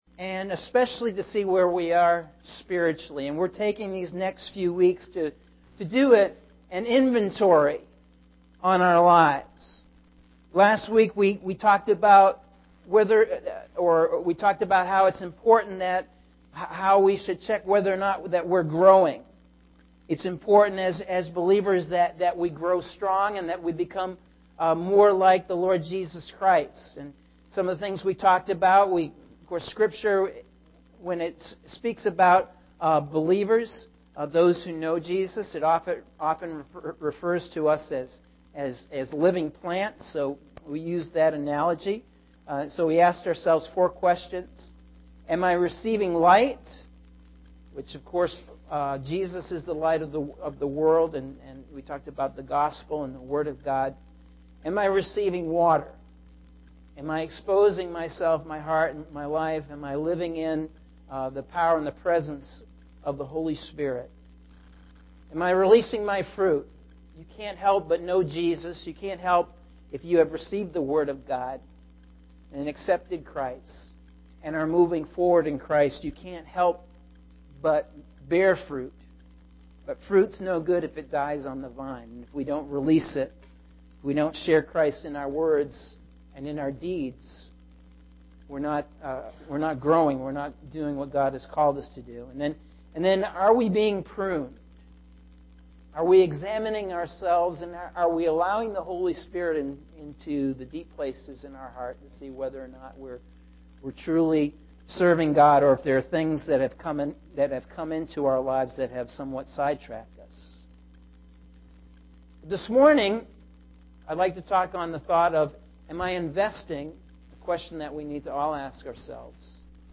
Sunday September 2nd – AM Sermon – Norwich Assembly of God